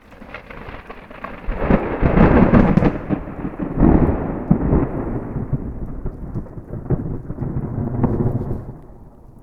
thunder-3.mp3